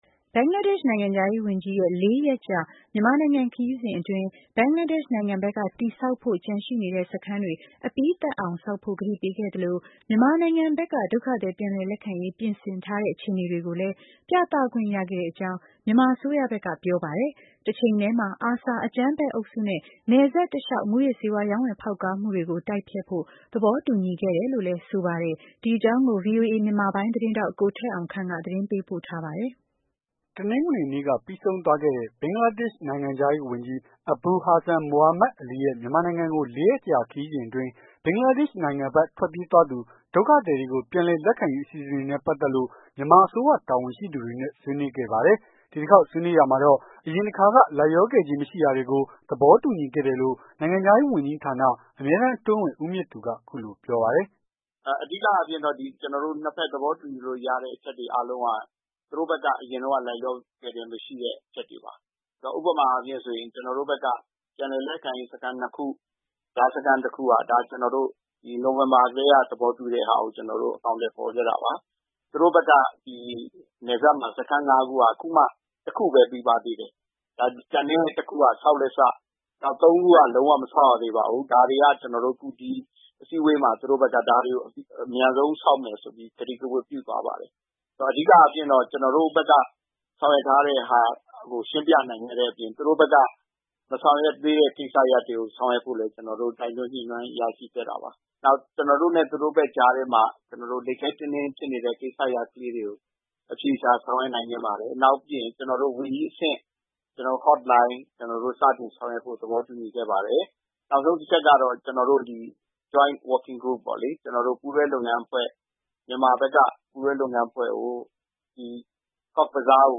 ဒီတခေါက်ဆွေးနွေးရာမှာတော့ အရင်တခါက လိုက်လျောခဲ့ခြင်းမရှိတာတွေကို သဘောတူညီခဲ့တယ်လို့ နိုင်ငံခြားရေး ဝန်ကြီးဌာန အမြဲတမ်း အတွင်းဝန် ဦးမြင့်သူ က အခုလိုပြောပါတယ်။